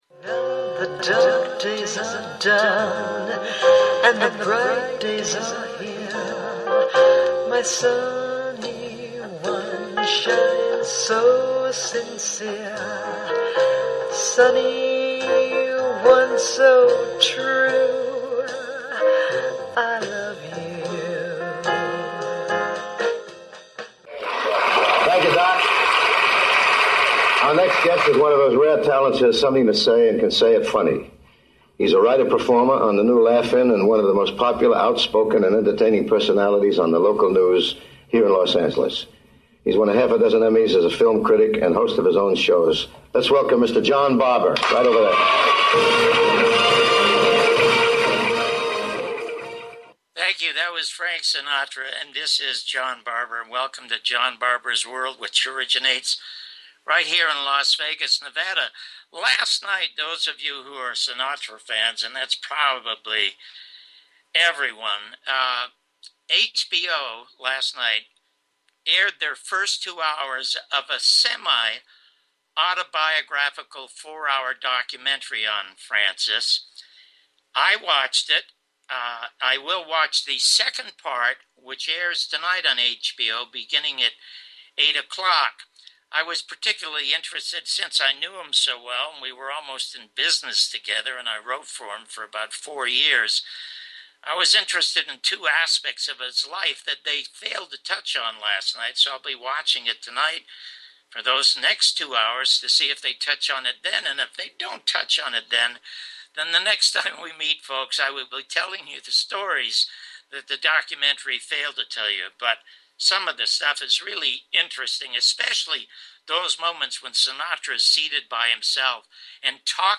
John Barbour's World with John Barbour and special guest Mark Crispin Miller